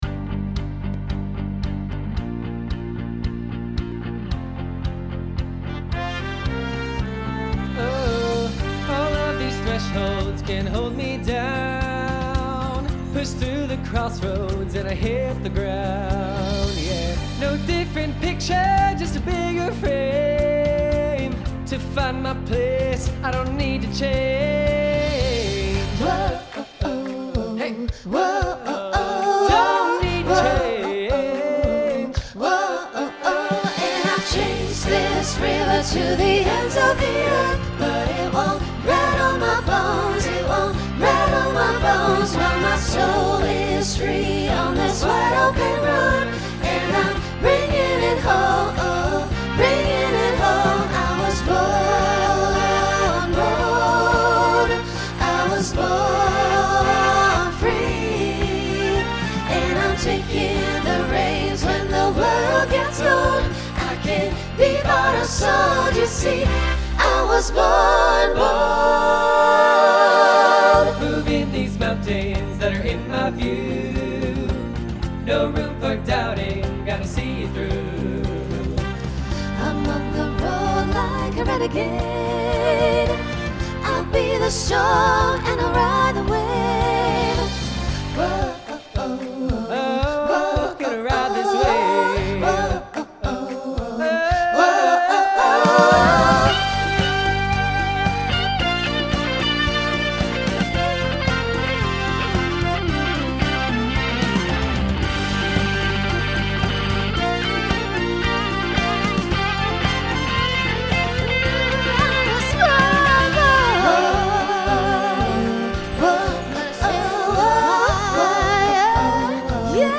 Folk , Rock Instrumental combo
Mid-tempo Voicing SATB